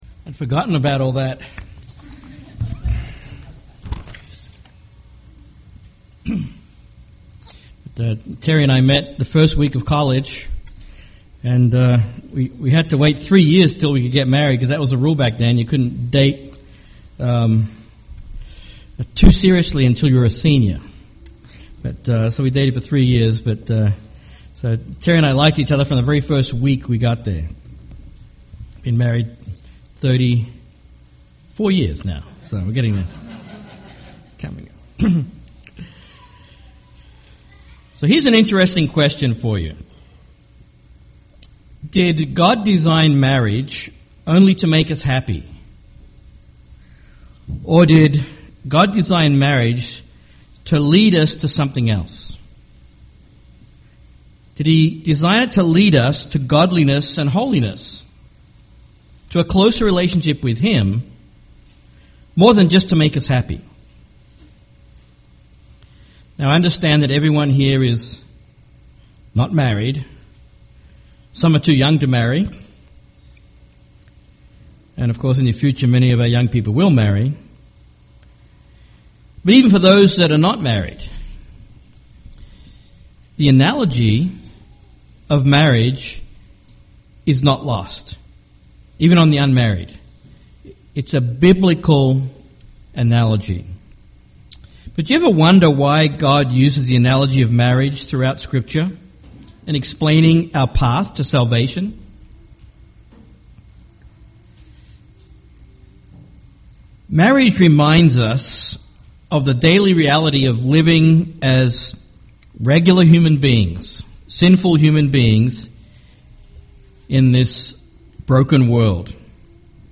UCG Sermon marriage covenant Baptism Godliness Transcript This transcript was generated by AI and may contain errors.